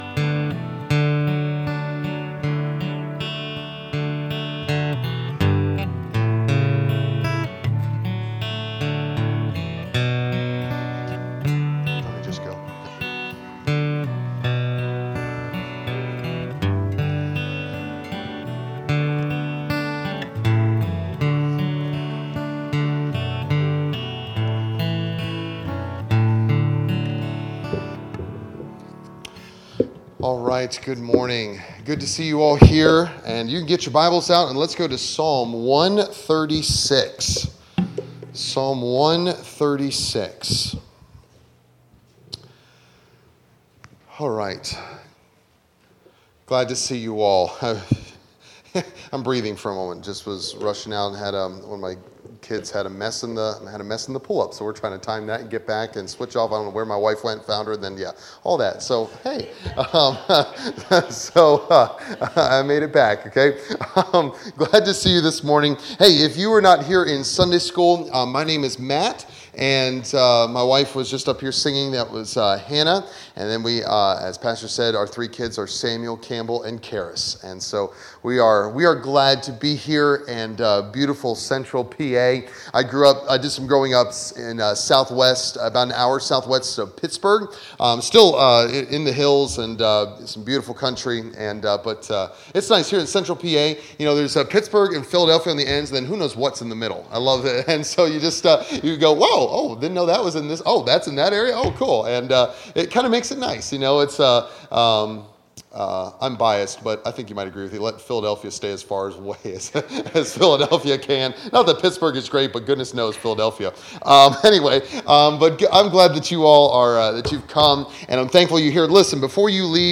Series: Revival Meeting Passage: Psalm 136